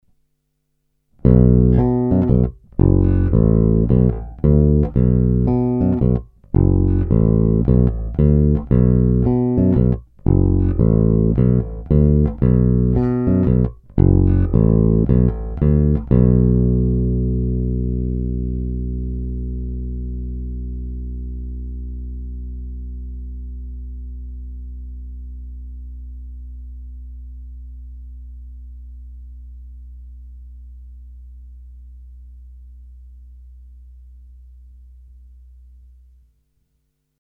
Není-li uvedeno jinak, následující nahrávky byly provedeny rovnou do zvukové karty a dále kromě normalizace ponechány v původním stavu.
Hra mezi snímačem a kobylkou